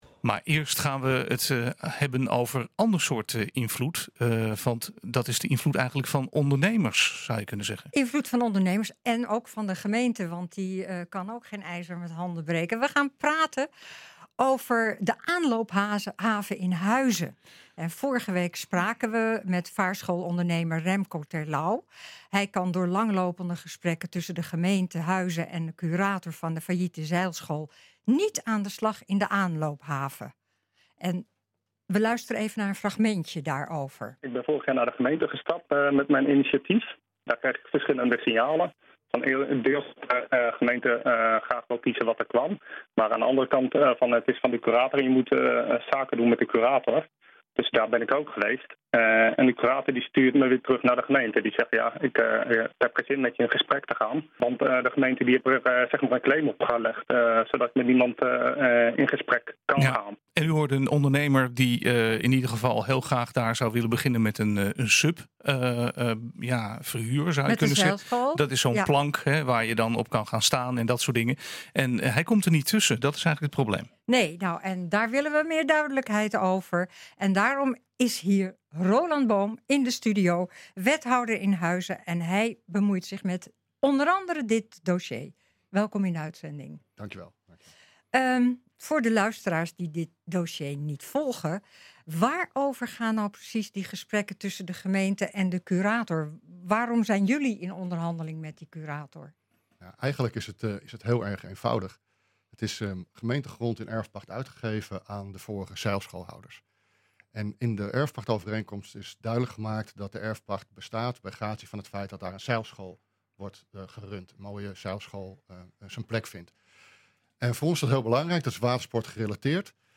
Om hierover duidelijkheid te verschaffen is aangeschoven verantwoordelijk wethouder Roland Boom.